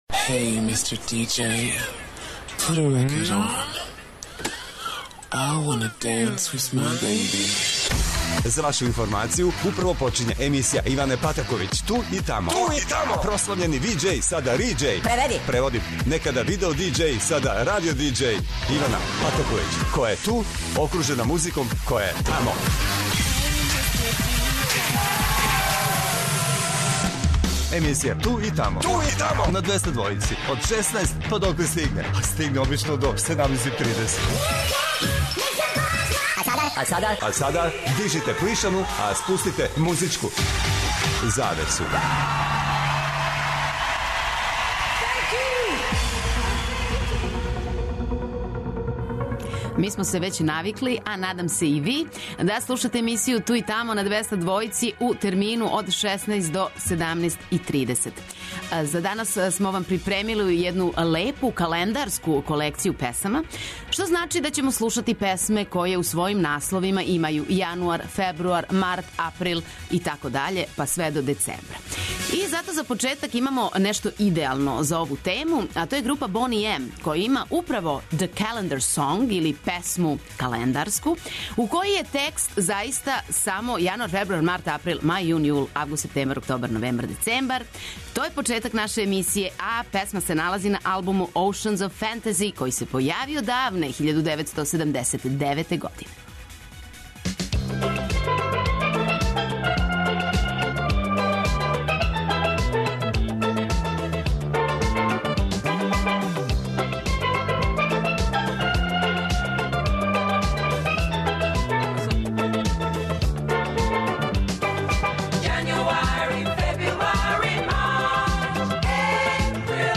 Прва децембарска емисија "Ту и тамо" доноси једну веома занимљиву - календарску тему. То значи да ће се на Двестадвојци завртети само нумере које у насловима имају јануар, фебруар и остале месеце...